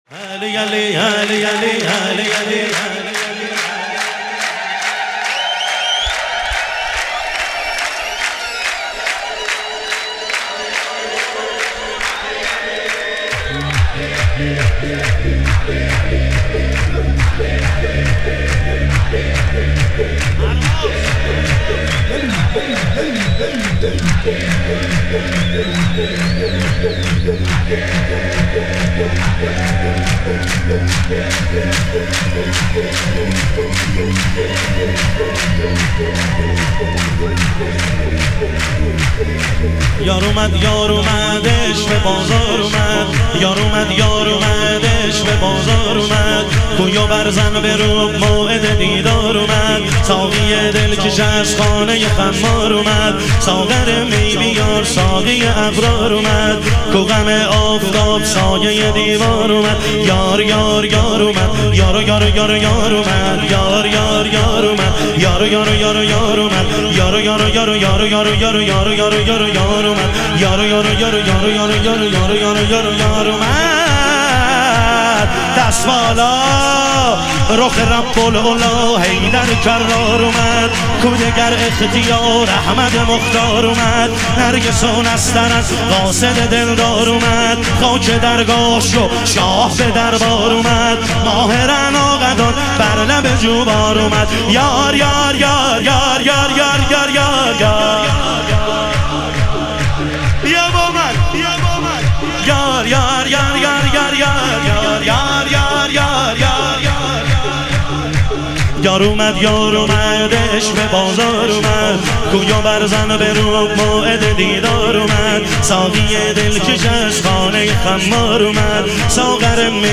ظهور وجود مقدس حضرت امیرالمومنین علیه السلام - شور